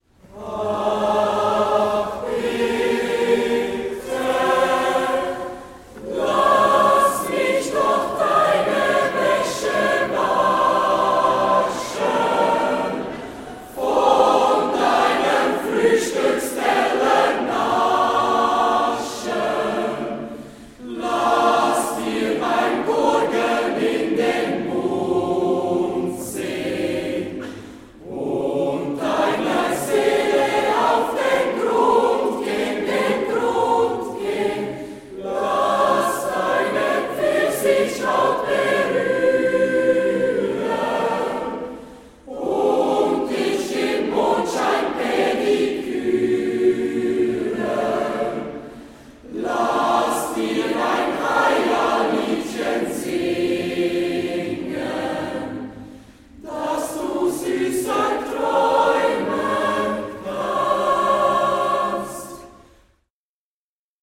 a Cappella , SATB